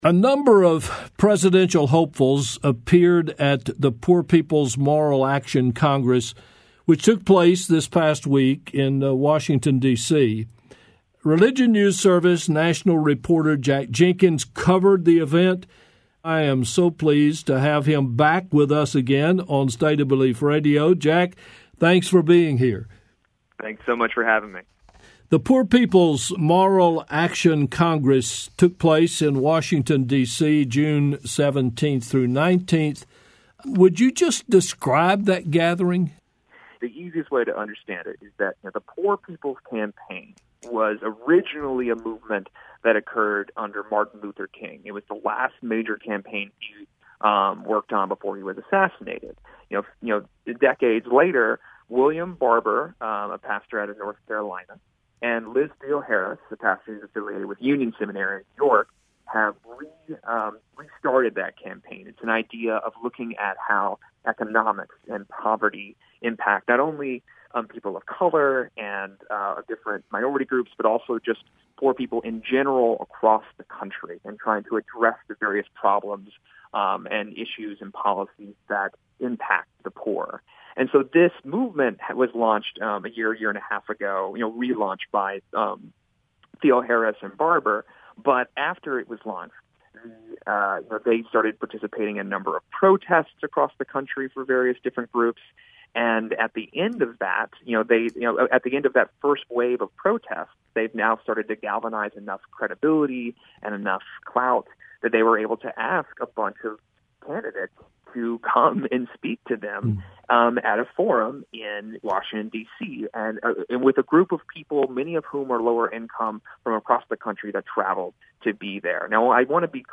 Hear the full June 22, 2019 State of Belief Radio program here.